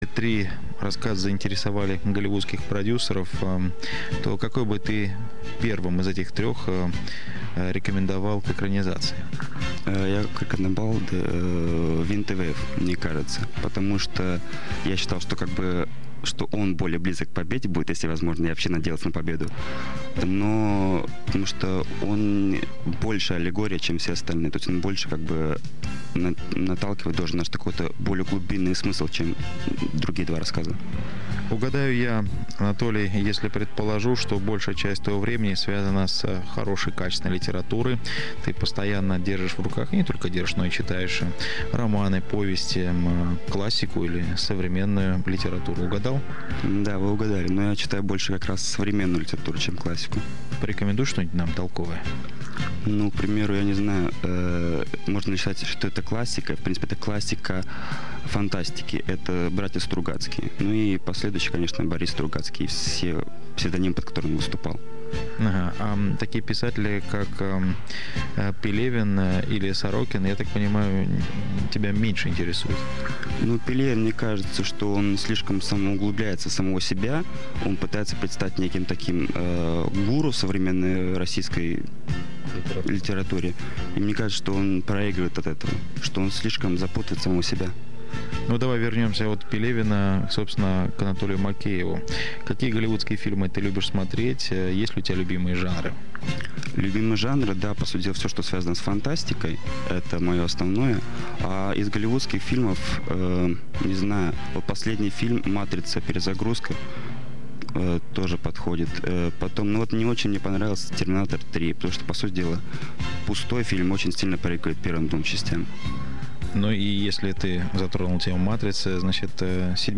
Во время записи я сильно волновался, и это ясно ощущается по тому, как я отвечал на задаваемые вопросы.
Первое интервью. Вторая часть - 2 мин 10 сек; 2,56 МB